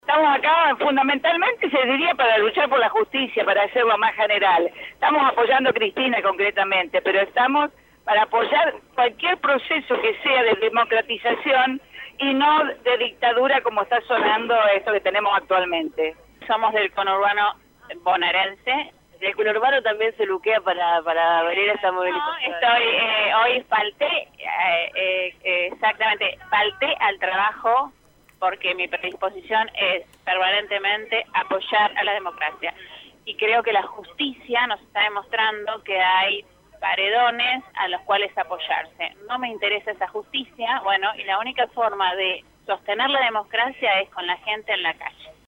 (13/4/2016) Citada a indagatoria por el juez Claudio Bonadio, en la causa de dólar futuro, esta mañana Cristina Fernández de Kirchner presentó un escrito en los Tribunales de Comodoro Py, mientras que en las afueras de la sede judicial una multitud proveniente de distintos puntos del país se congregó para brindar su apoyo a la ex mandataria.